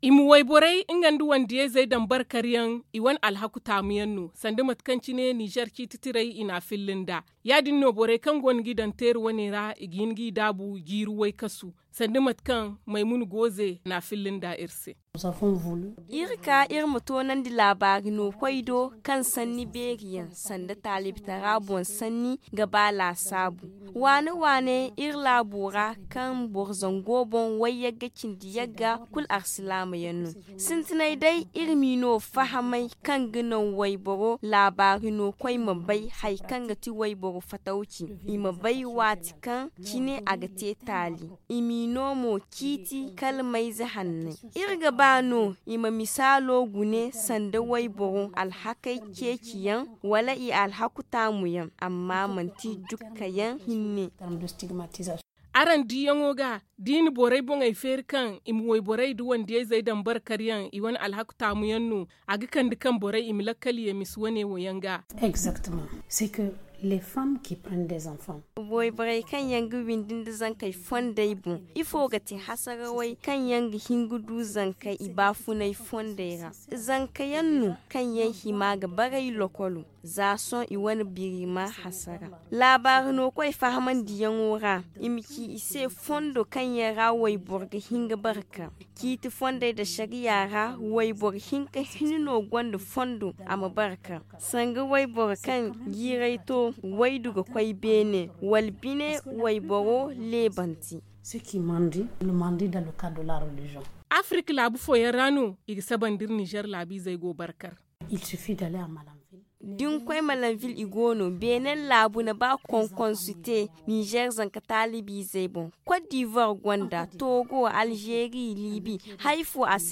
C’est dans un entretien […]